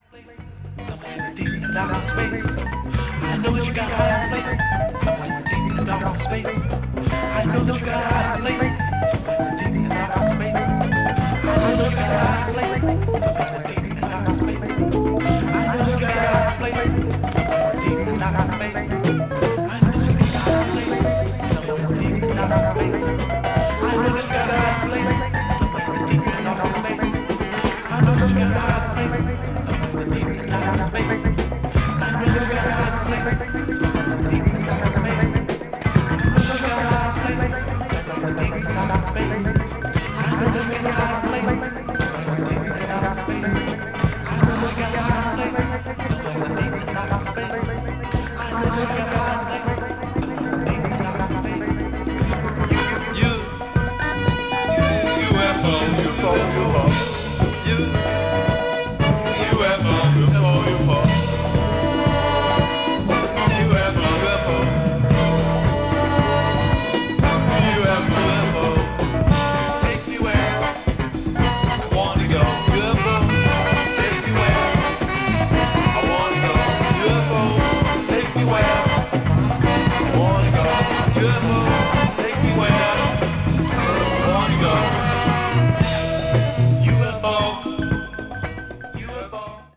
media : EX-/EX-(薄いスリキズによるわずかなチリノイズが入る箇所あり)